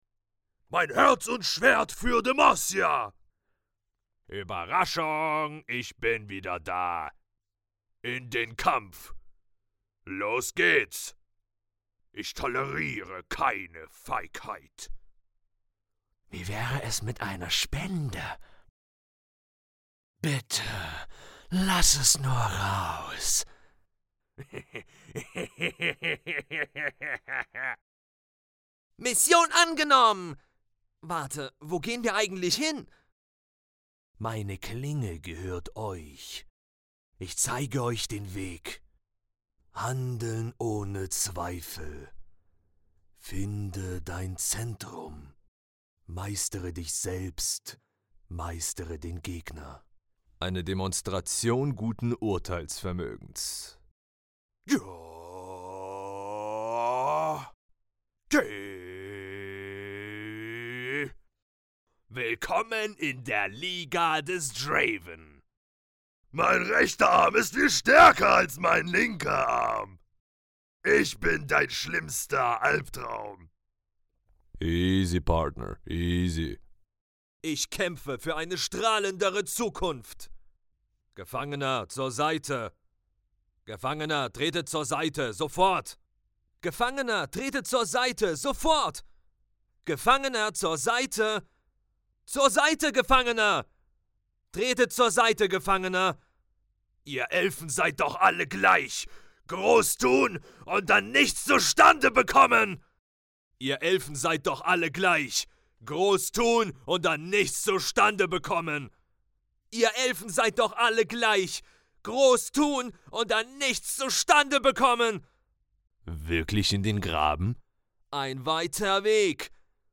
Professioneller, deutscher, männlicher Sprecher mit tiefer, angenehmer, autoritärer und entspannter Stimme.
Sprechprobe: Sonstiges (Muttersprache):
Professional German male voiceover with a deep, pleasant, authoritative and calming voice.
Germanvoice_Videospiele_.mp3